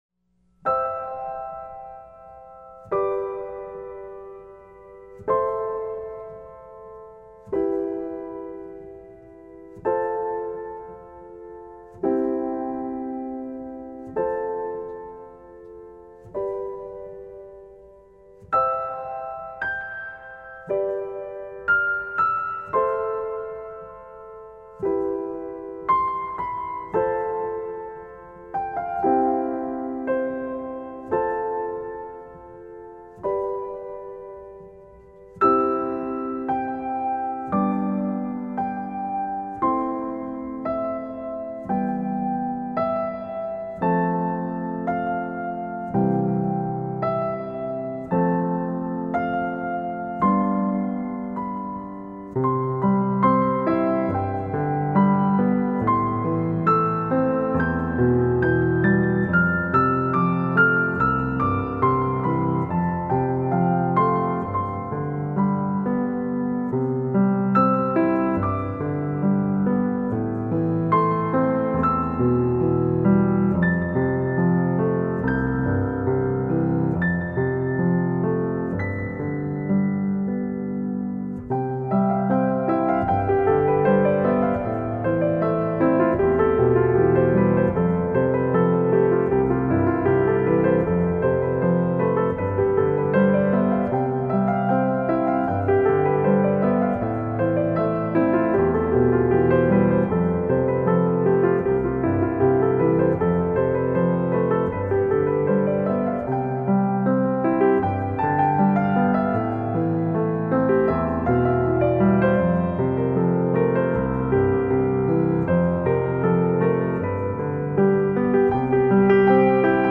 اهنگ بی کلام پیانو زیبا